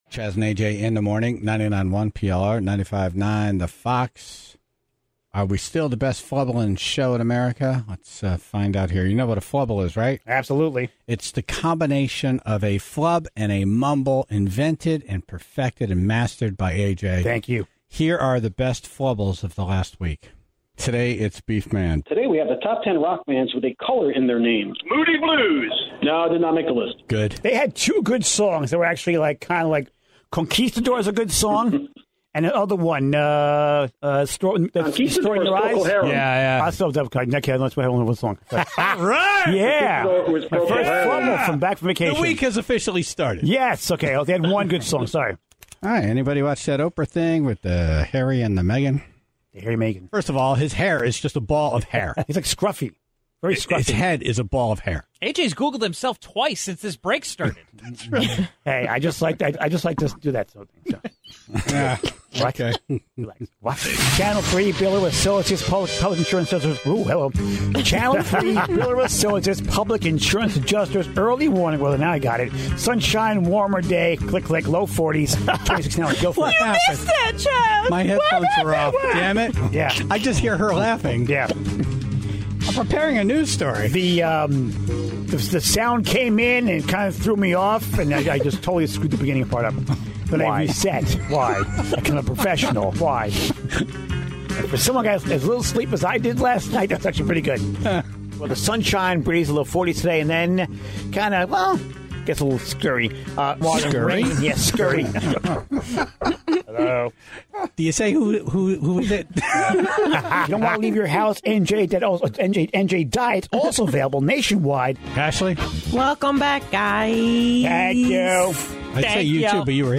A flubble is a combination of a flub and mumble, when you screw up speaking so badly that it becomes hysterical.